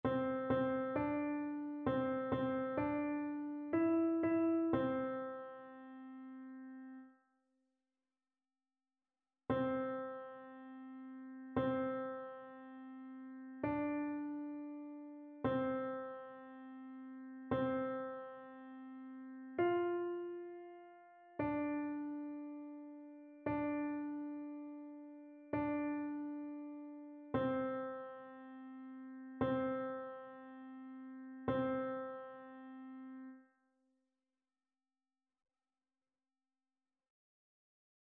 Chœur
annee-c-temps-ordinaire-7e-dimanche-psaume-102-alto.mp3